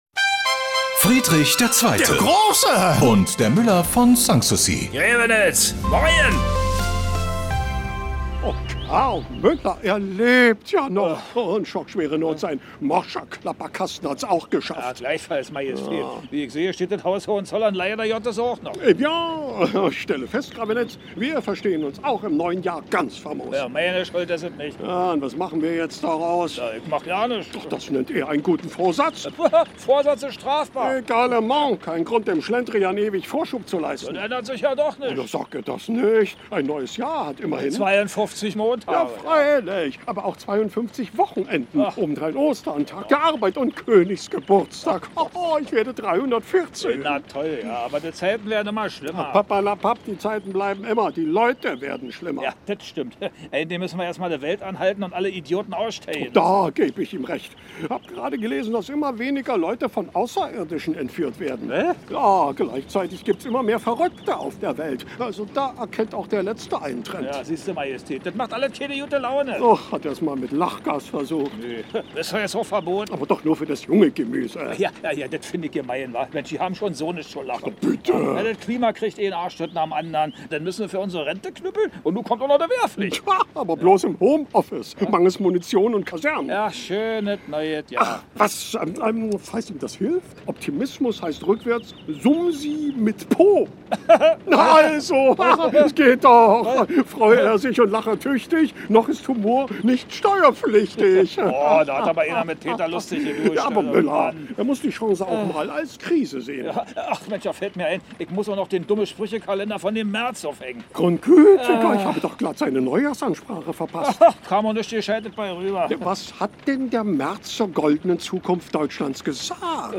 Der legendäre Nachbarschaftsstreit setzt sich bis heute fort: Preußenkönig Friedrich II. gegen den Müller von Sanssouci. Immer samstags kriegen sich die beiden bei Antenne Brandenburg in die Haare.
Regionales , Comedy , Radio